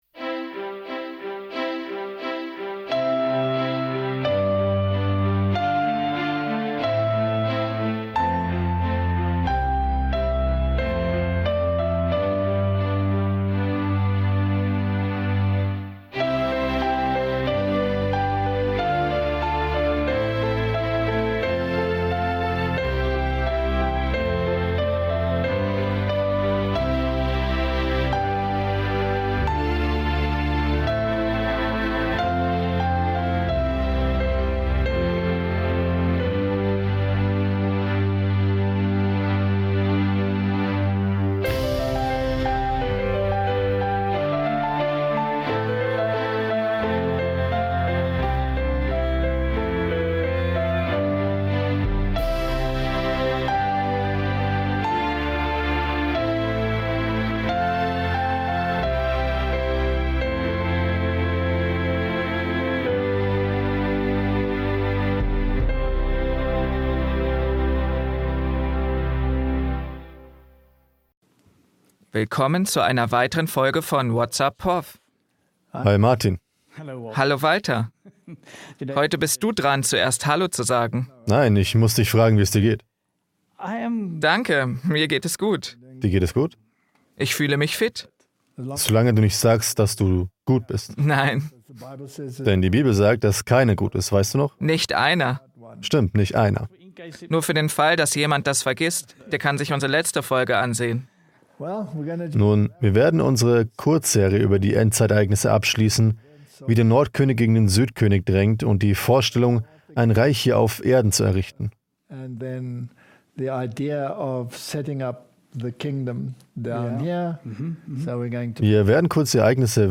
Whats Up, Prof? (Voice Over)